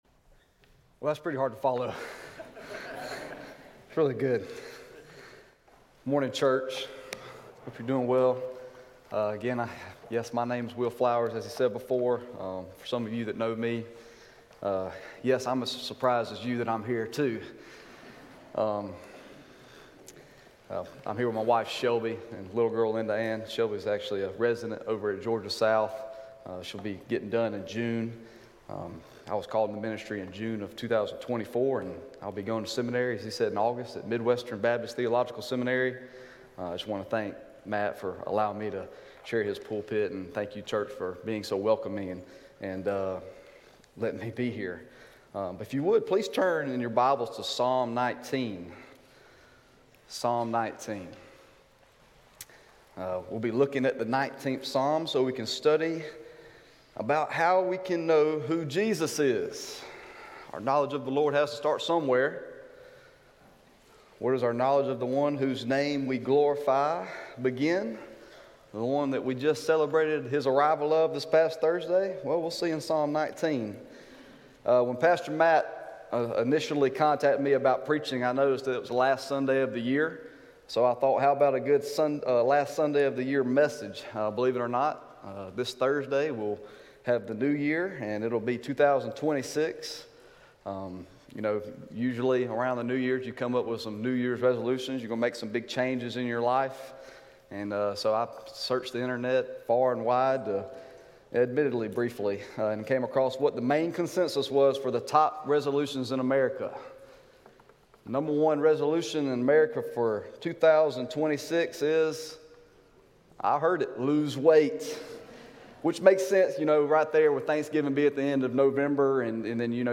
New Year's Resolution: Daily Bible Reading Sermon